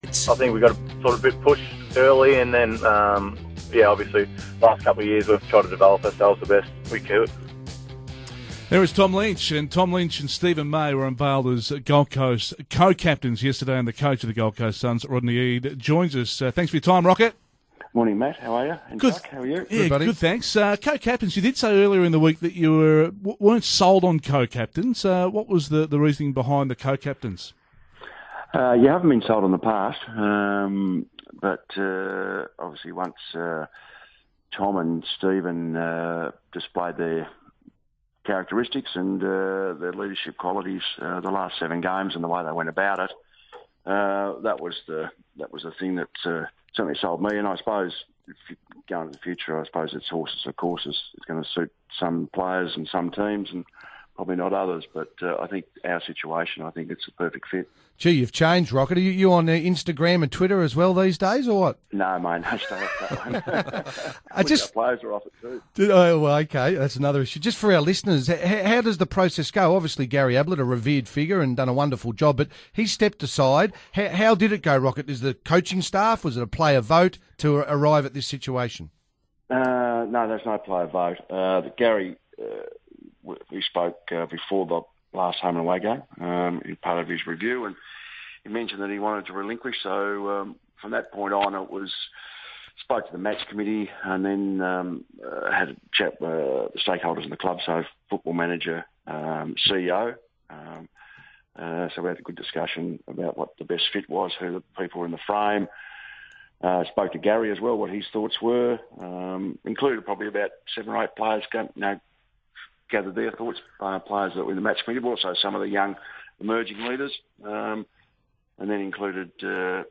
Senior coach Rodney Eade joined the SEN Breakfast Radio team.